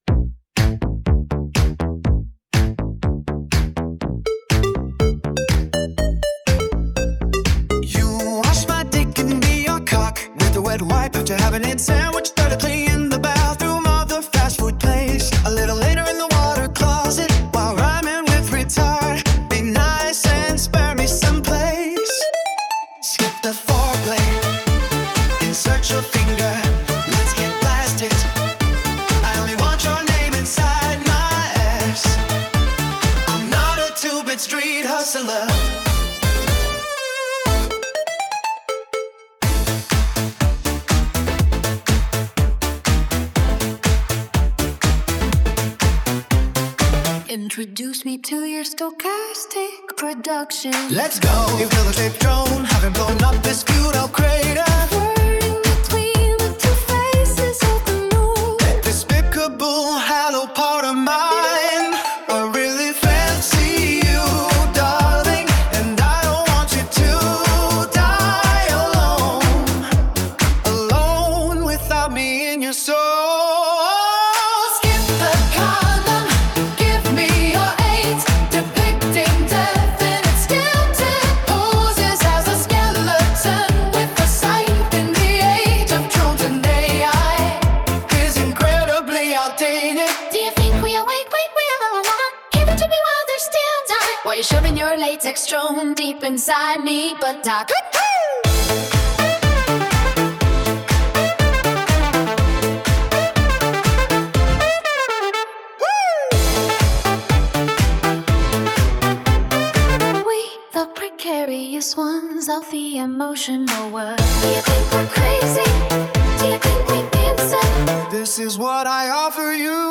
vocals
backing vocals
synthesizer, drum machine and sample
electronic drums
8-track mixtape 2006